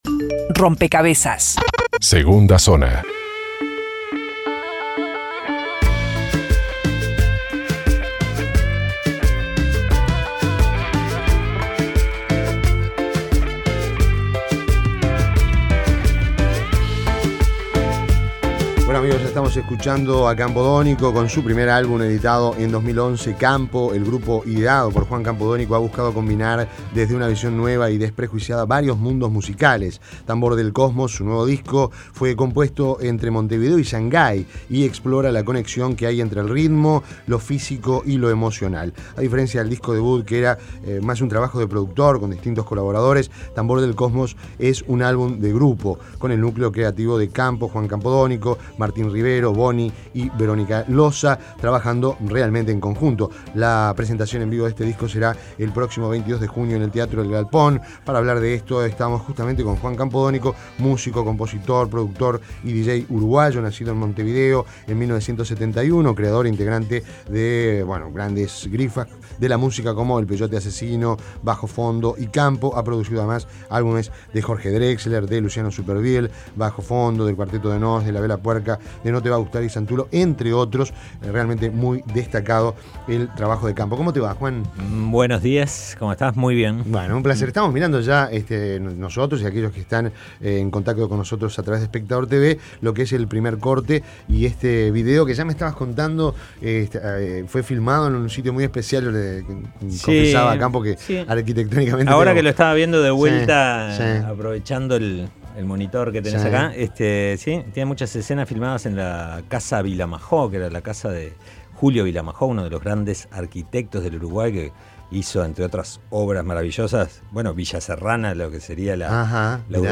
Entrevista en Rompkbzas Campo cósmico Juan Campod�nico